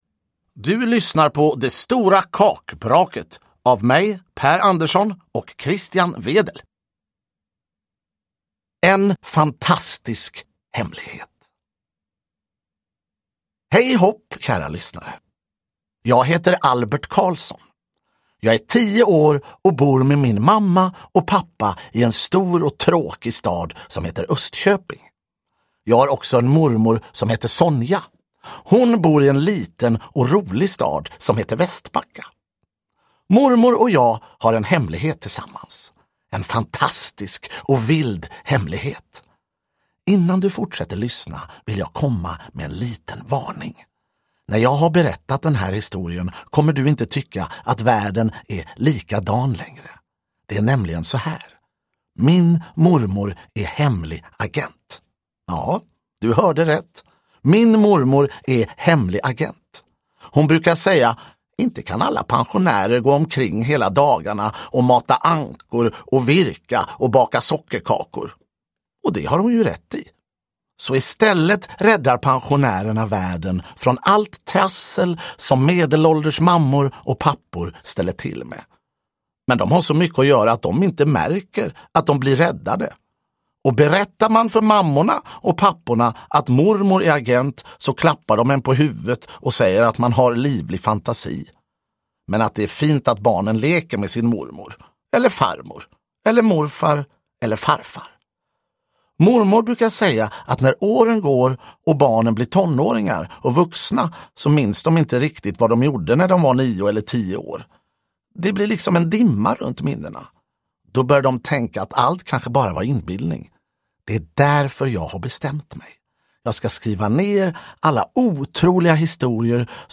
Det stora kakbraket – Ljudbok
Uppläsare: Per Andersson